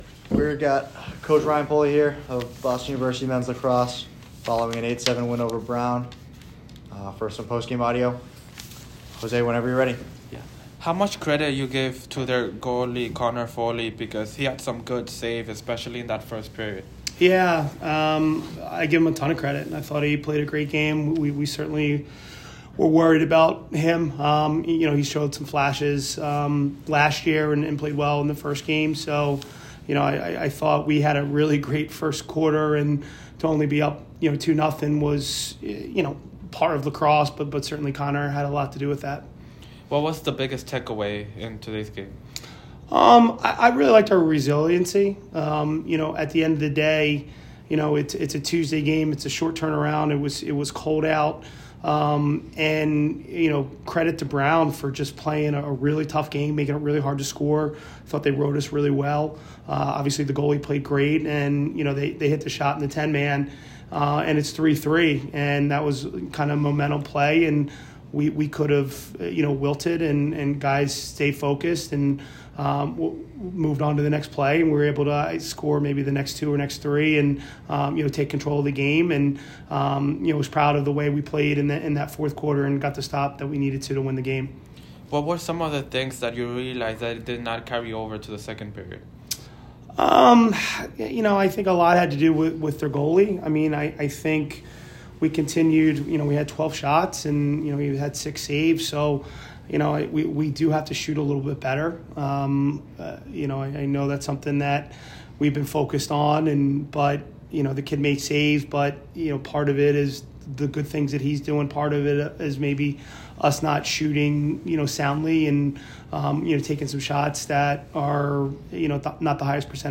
Men's Lacrosse / Brown Postgame Interview (2-18-25)